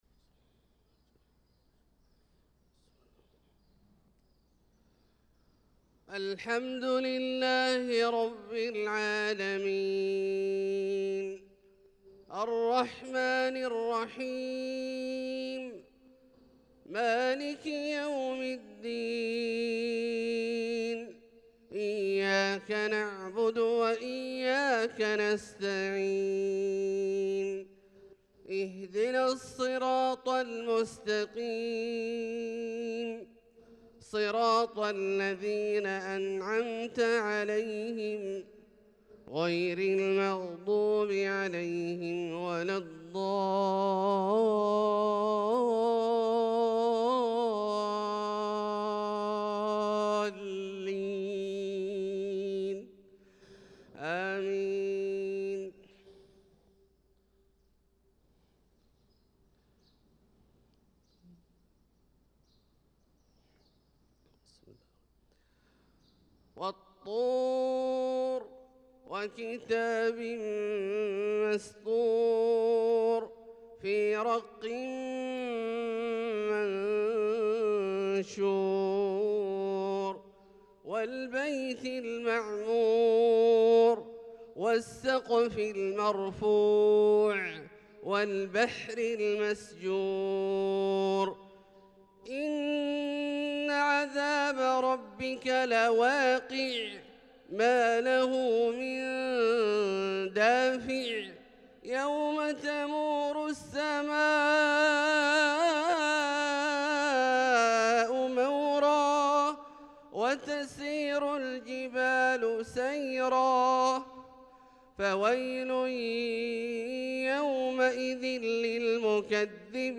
صلاة الفجر للقارئ عبدالله الجهني 3 ذو القعدة 1445 هـ